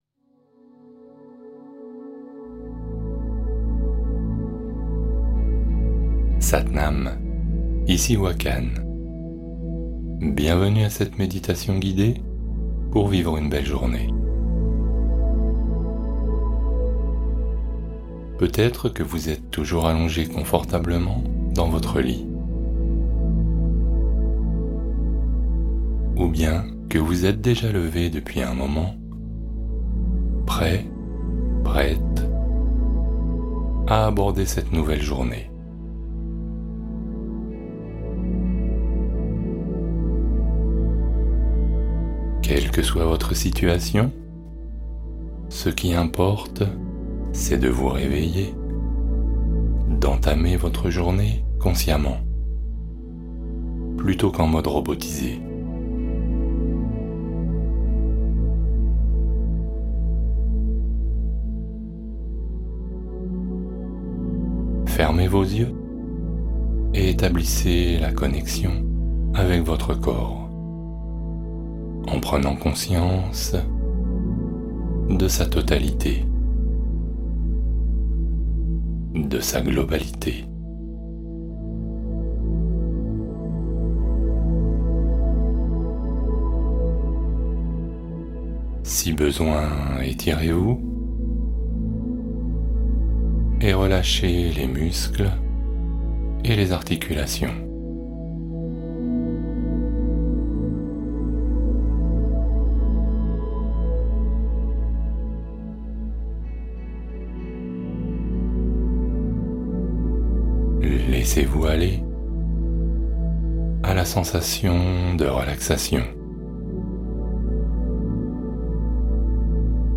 Commencez votre journée avec sérénité : méditation matinale anti-stress ultra-efficace